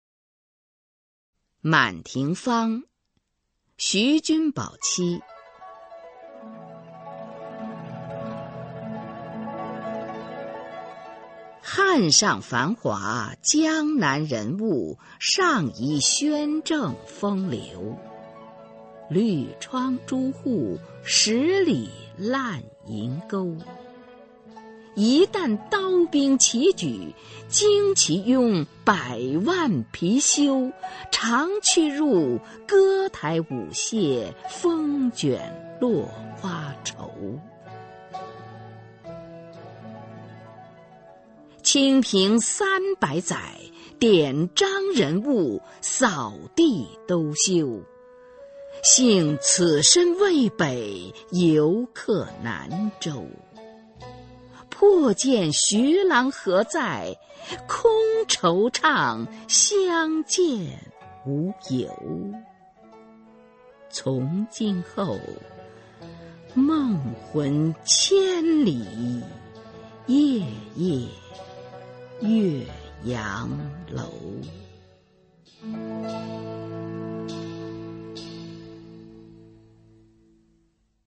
[宋代诗词诵读]徐君宝妻-满庭芳 宋词朗诵